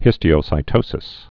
(hĭstē-ōsī-tōsĭs)